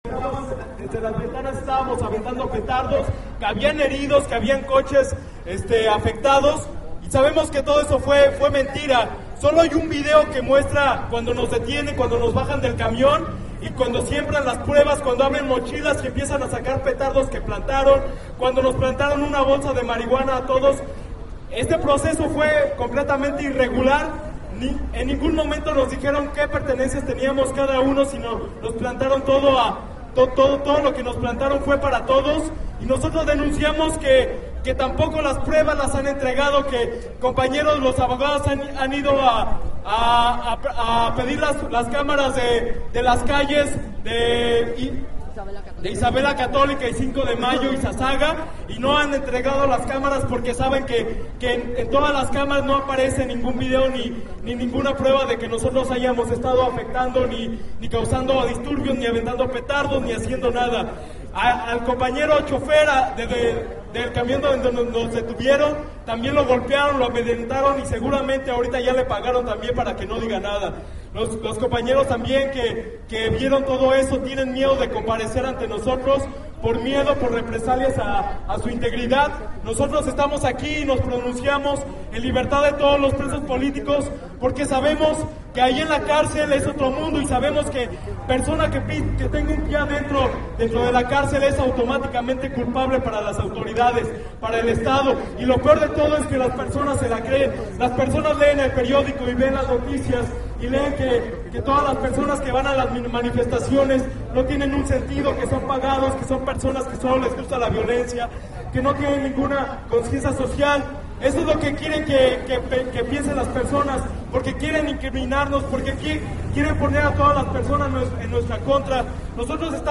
Pasado del medio día, inició el mitin que daría paso a la instalación de las carpas para iniciar la huelga de hambre.
Participación de procesado del 2 de octubre